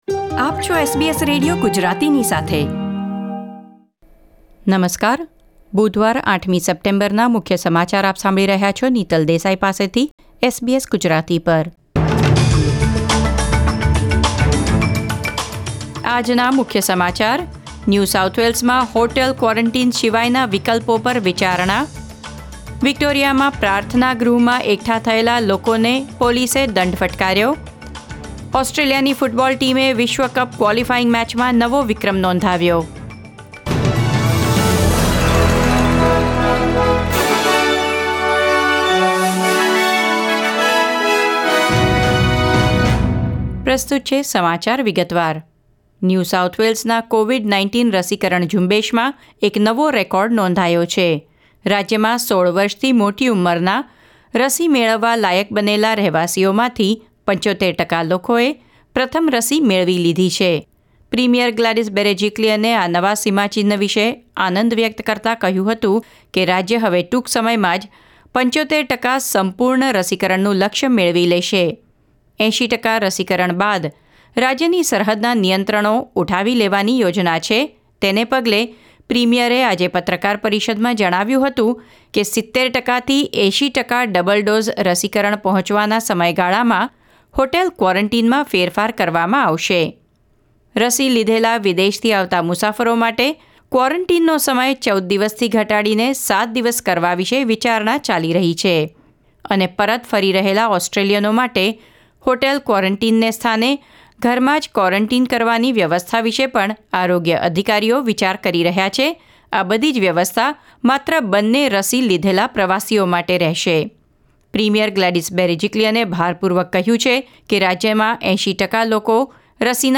SBS Gujarati News Bulletin 8 September 2021